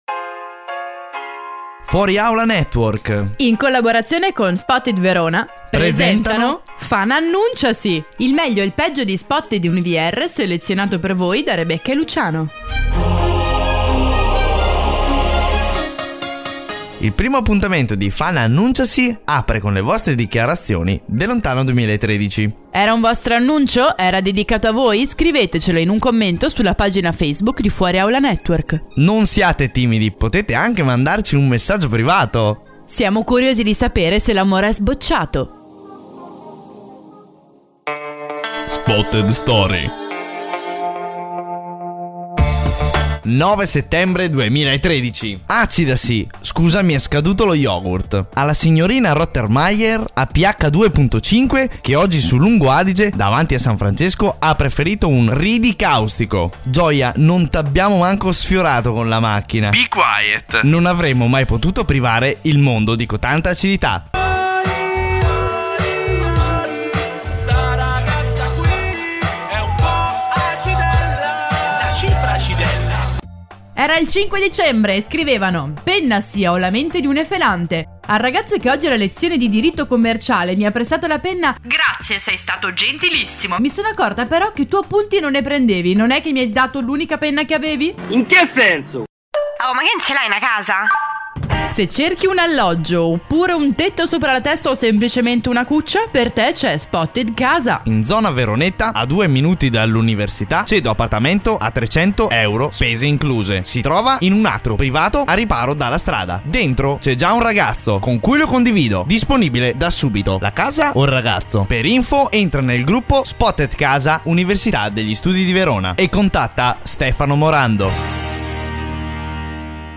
FuoriAulaNetwork - la Web Radio dell'Università degli Studi di Verona